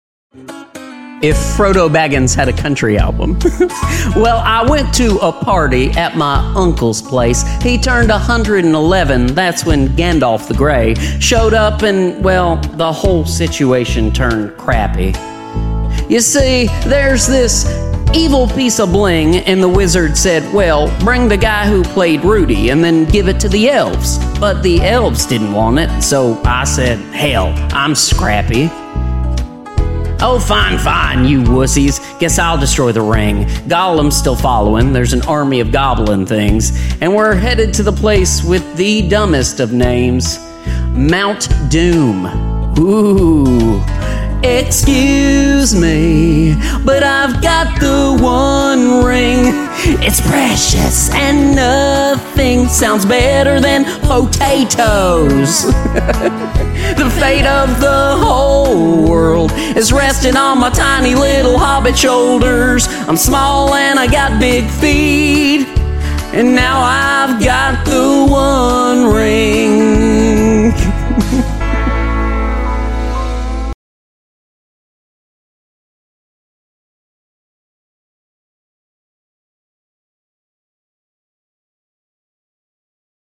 comedy song parody